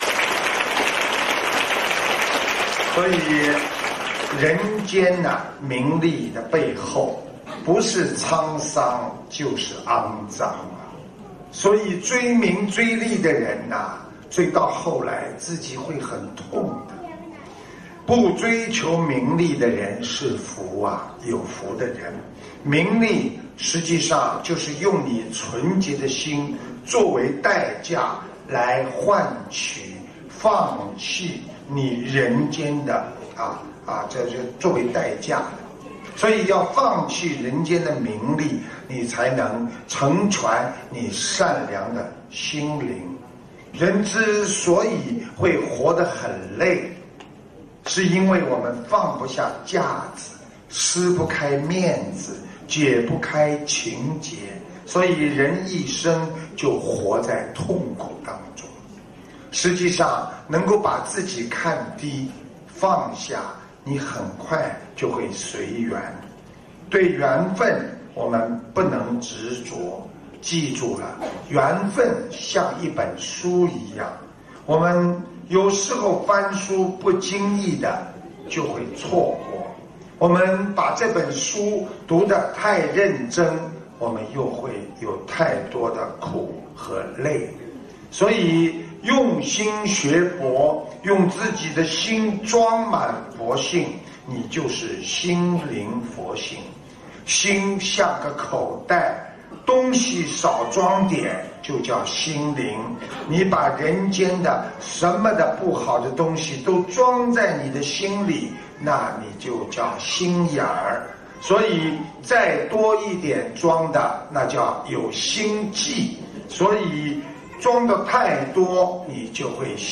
▶ 语 音 朗 读 点击进入 ☞ 首页 > 每日 畅听 平时你们难过、烦恼， 多听听师父 的录音 ， 会笑的。